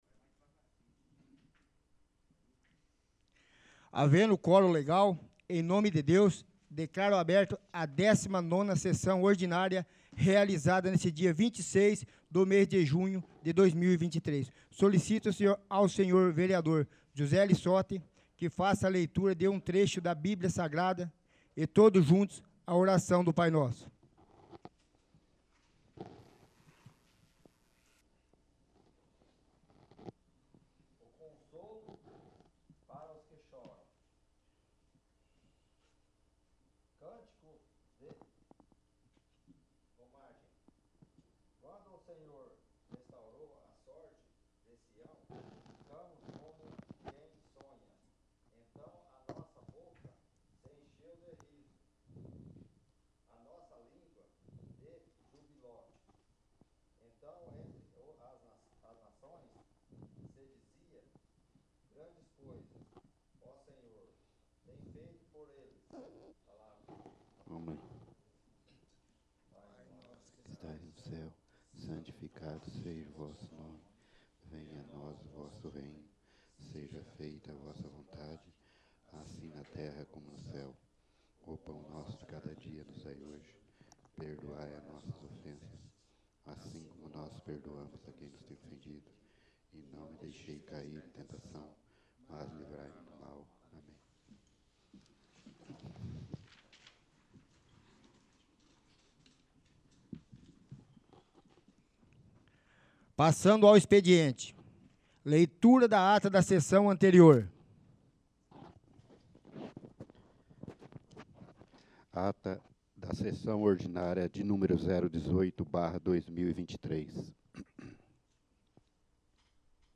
19º. Sessão Ordinária